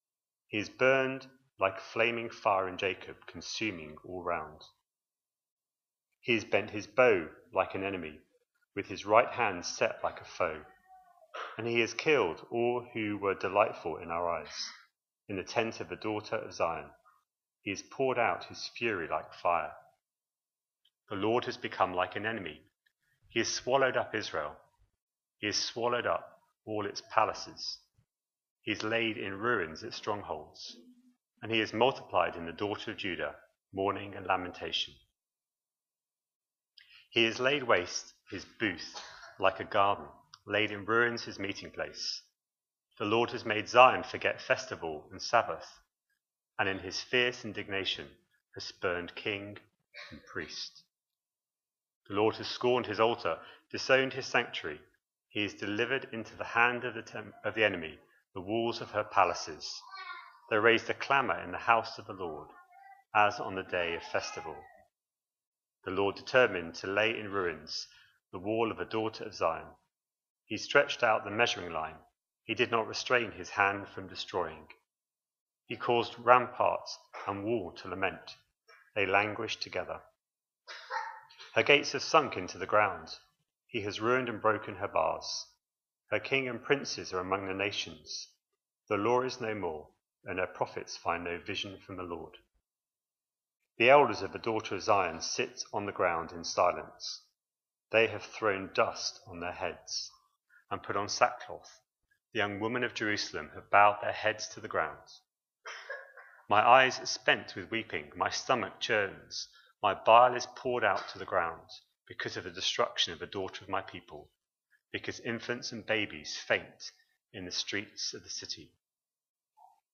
The latest sermons from Trinity Church Bradford.